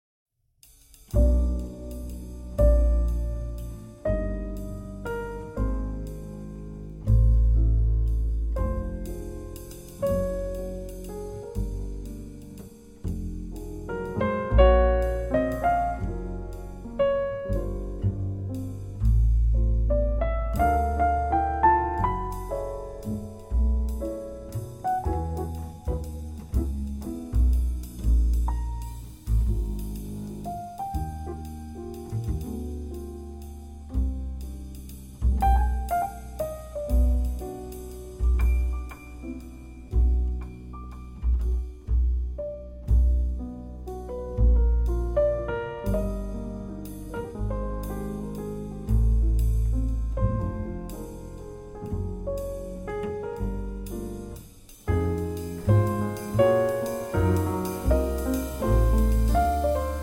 piano
drums
bass